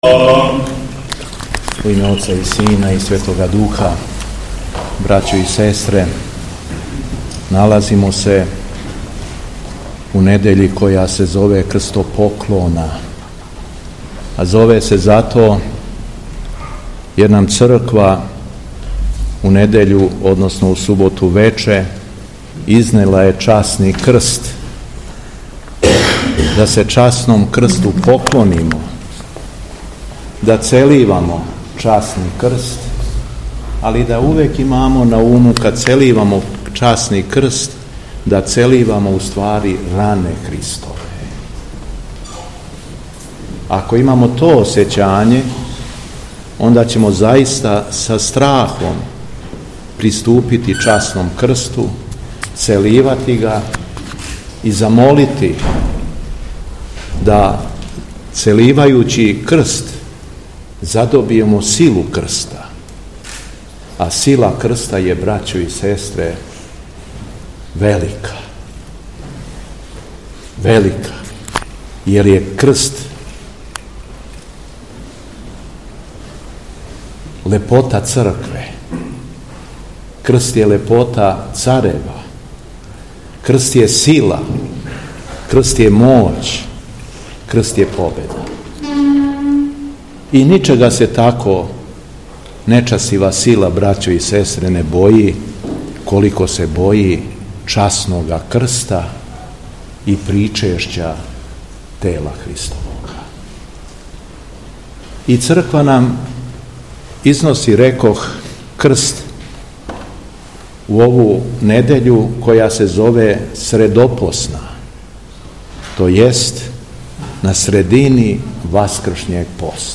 Беседа Његовог Високопреосвештенства Митрополита шумадијског г. Јована
У својој беседи Митрополит се обратио сабраном народу: